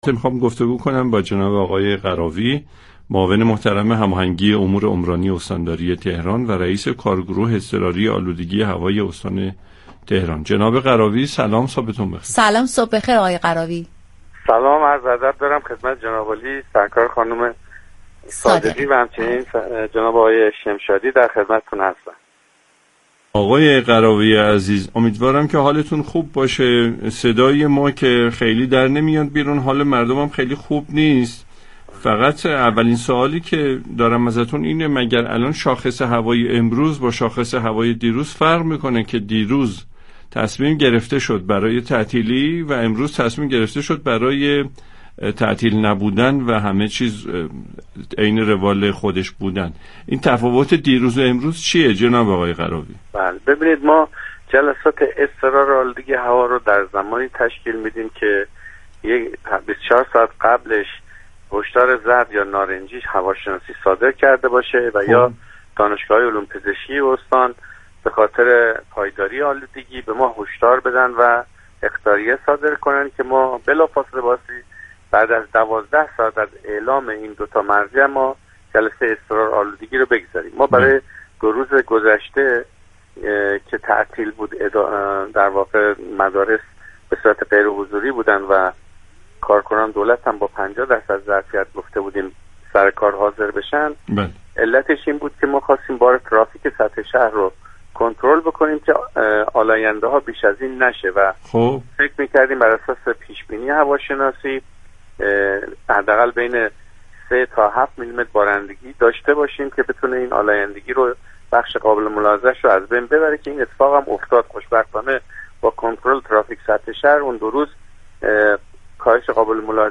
به گزارش پایگاه اطلاع رسانی رادیو تهران، میرمحمد غراوی، معاون هماهنگی امور عمرانی استانداری تهران و رئیس كارگروه اضطرار آلودگی هوای استان تهران در گفت و گو با «بام تهران» اظهار داشت: جلسه اضطراری كارگروه آلودگی هوا با توجه به پایداری آلودگی هوا و بر اساس هشدارهای هواشناسی و دانشگاه علوم پزشكی امروز 20 آذر ساعت 12:00 تشكیل می‌شود.